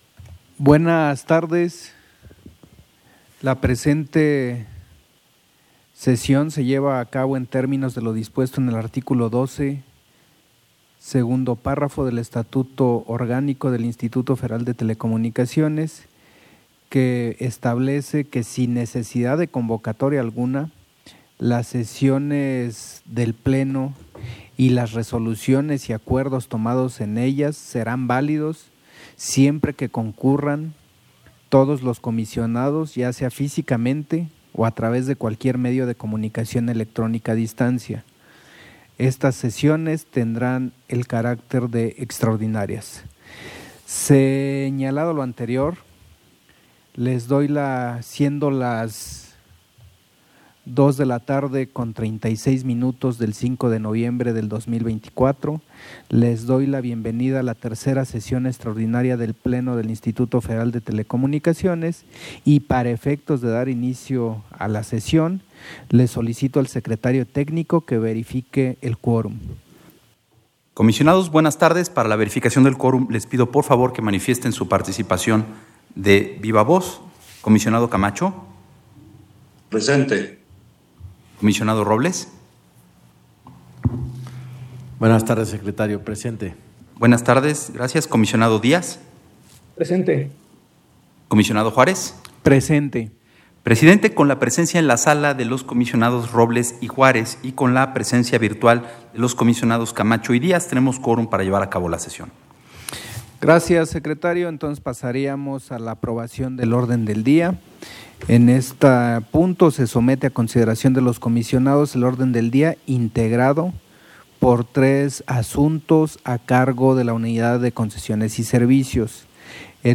Audio de la sesión